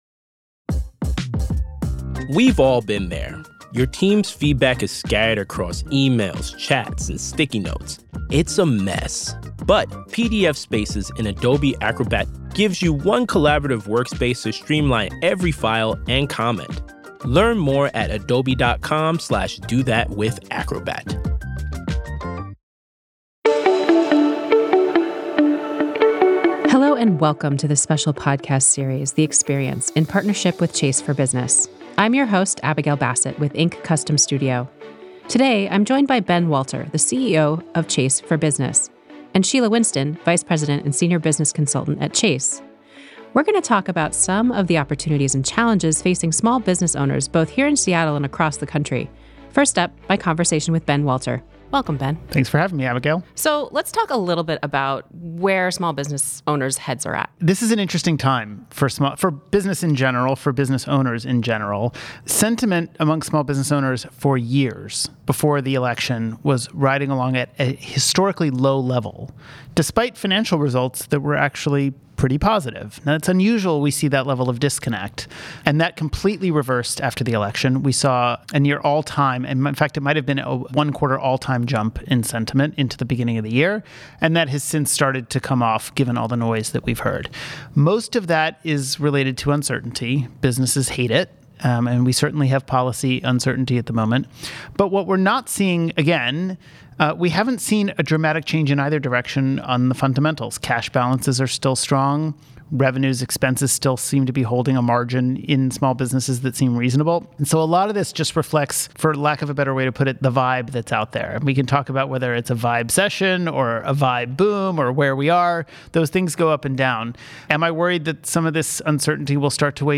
I sat down with leaders in Seattle from Chase for Business for this quick podcast for Inc. Magazine.